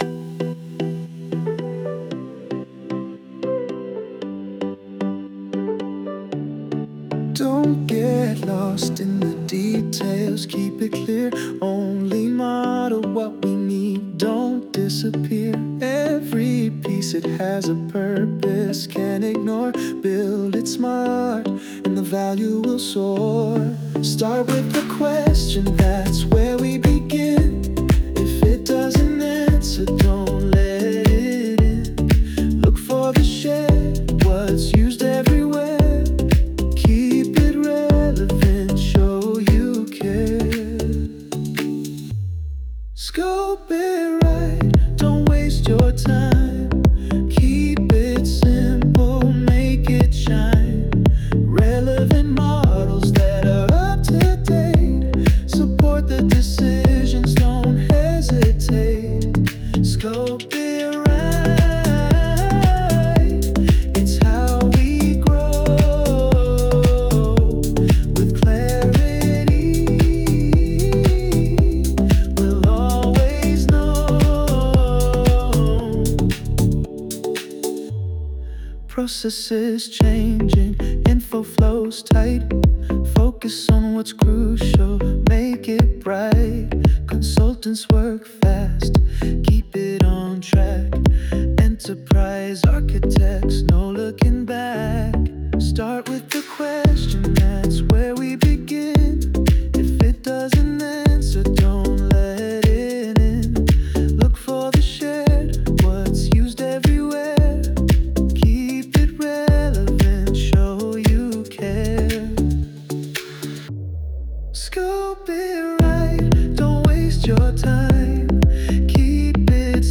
Tropical House · 114 BPM · Eng